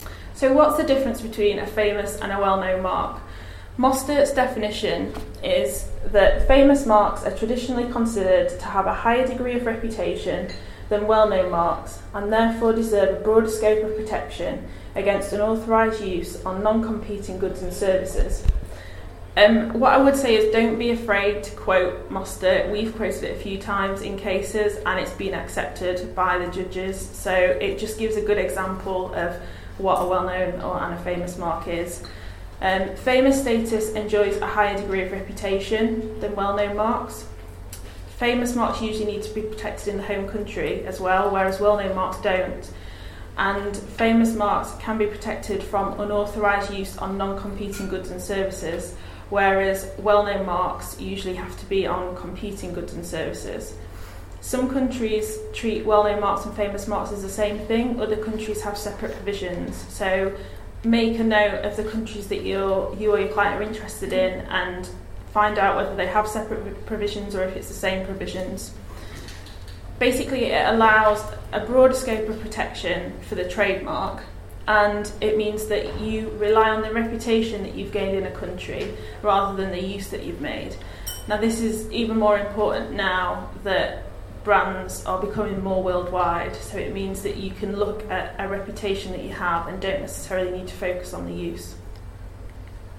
Recorded at the Institute of Trade Mark Attorneys's Autumn Seminar in September 2015.